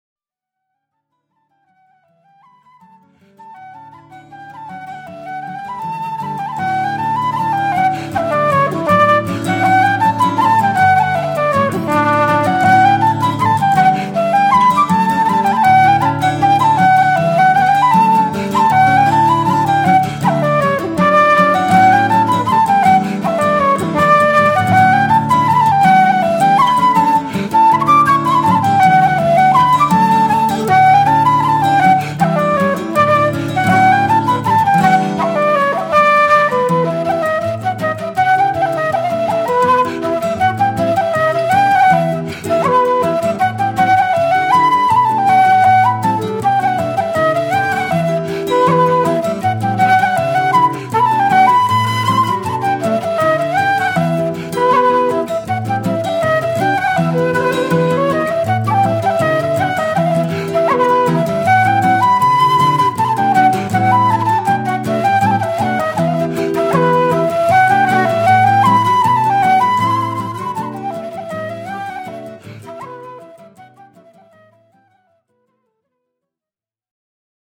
flûte traversière en bois
guitare